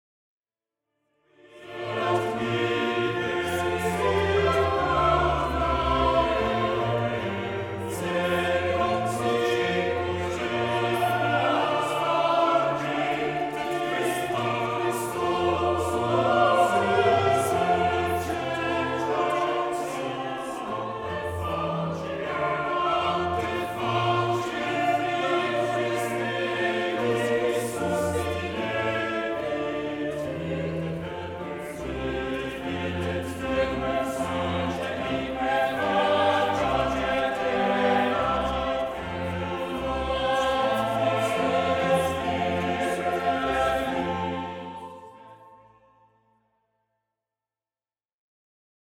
Un manifeste flamboyant de la révolution baroque